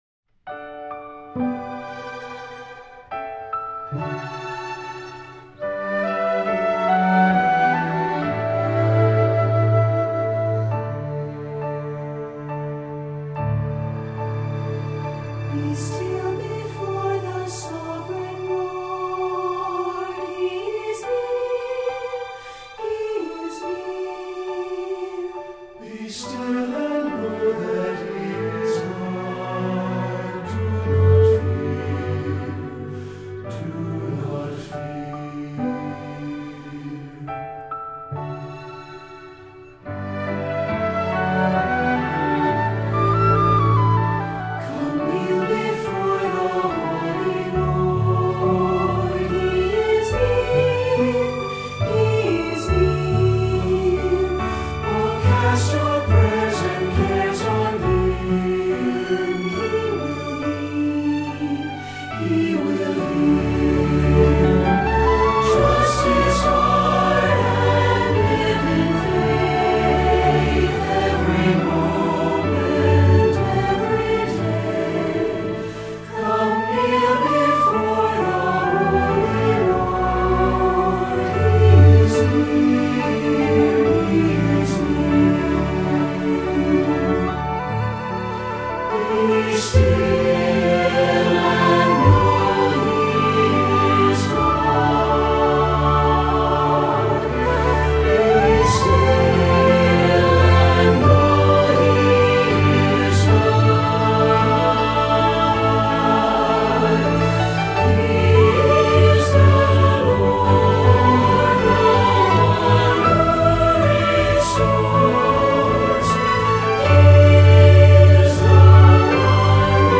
SATB and Piano Level